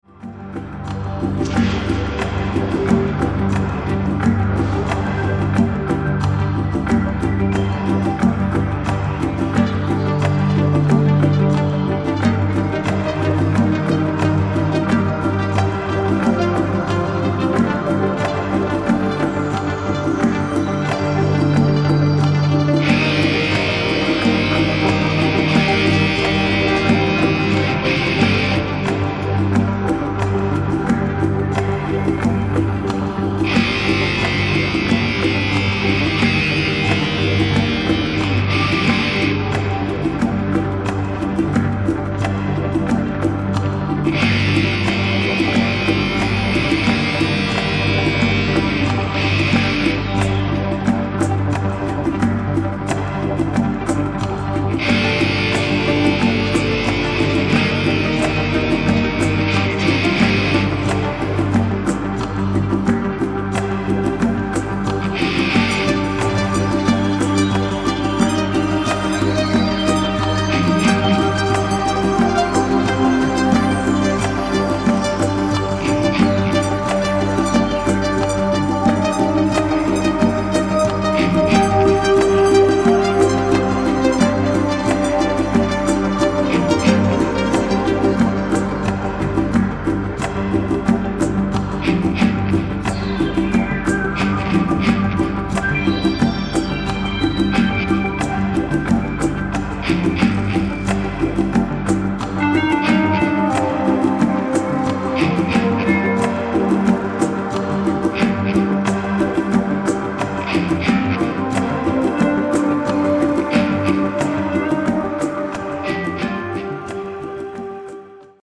20.02.2005 w klubie DELTA